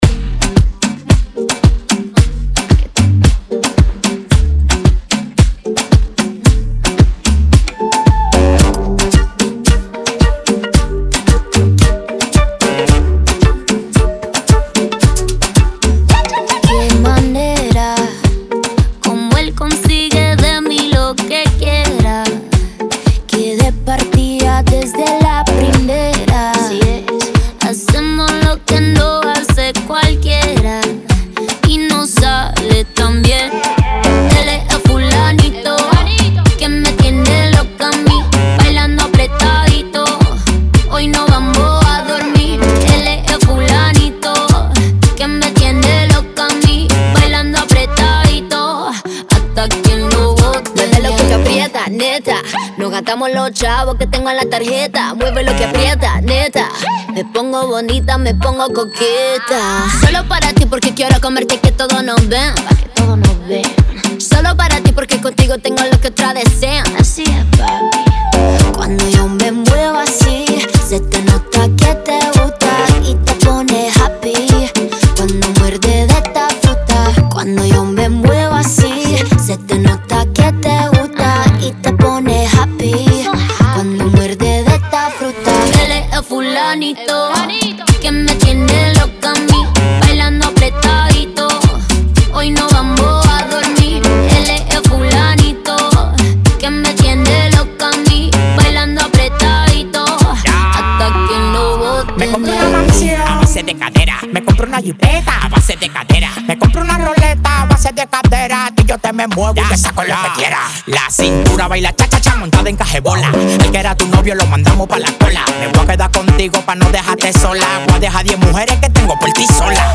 Genre: Mambo.